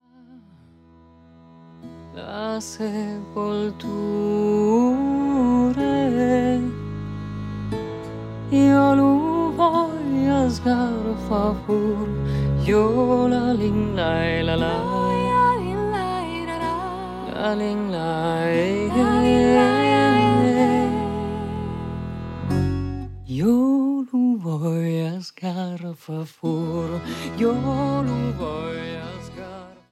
(canzone della tradizione, rivisitazione musicale)